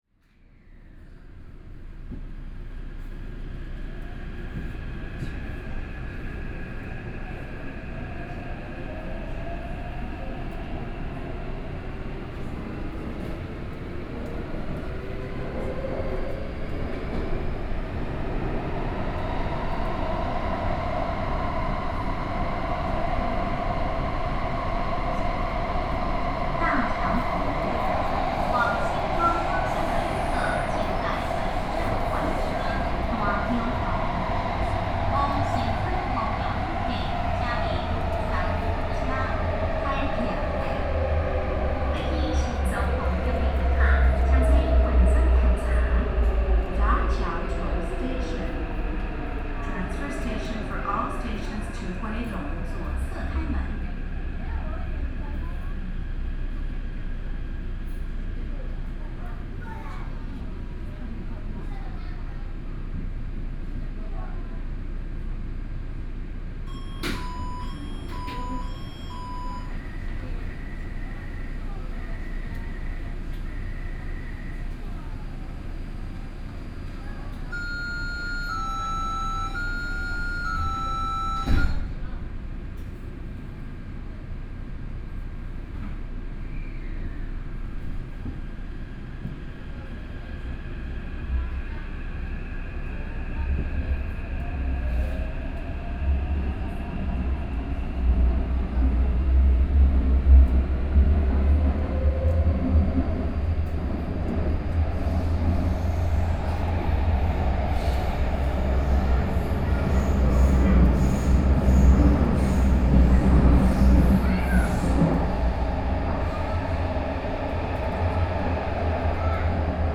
Sanchong District,New Taipei City - Luzhou Line (Taipei Metro)
from Minquan West Road Station to Sanmin Senior High School Station, Binaural recordings ,Best with Headphone ,Sony PCM D50 + Soundman OKM II